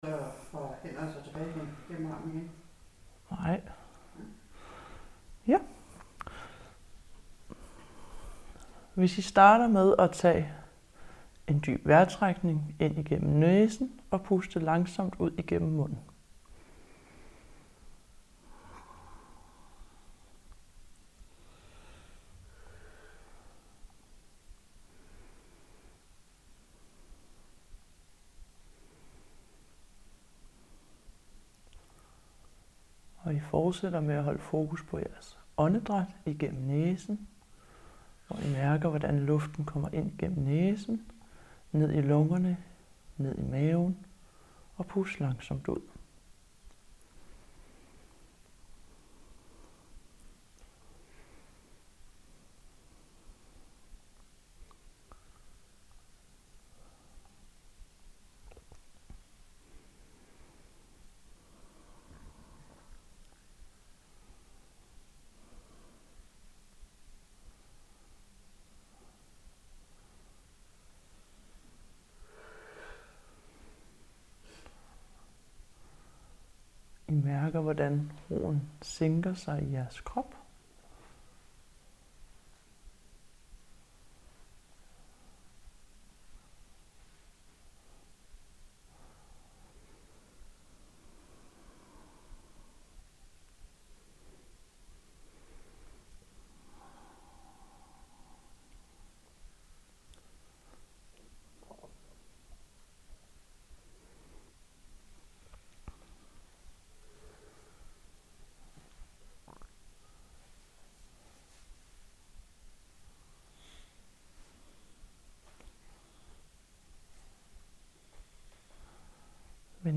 Kropsscanning